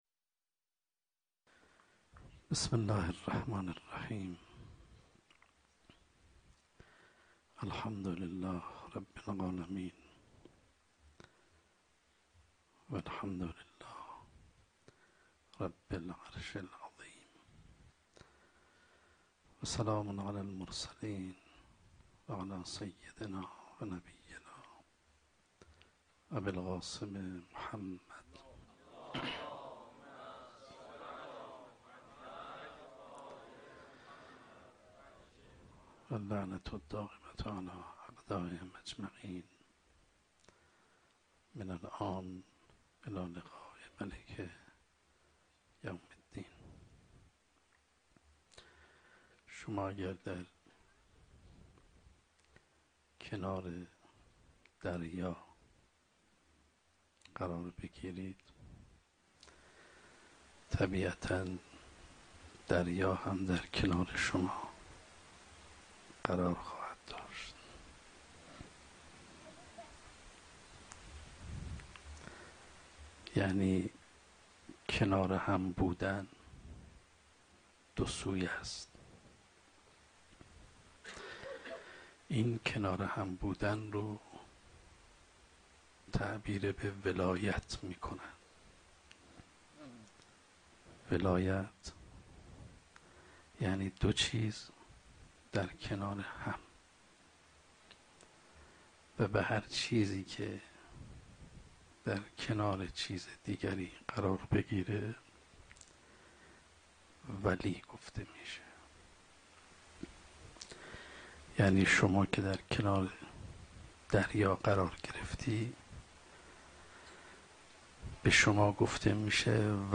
شب هفتم محرم 95 - سخنرانی - دنیا و استفاده انسان از آن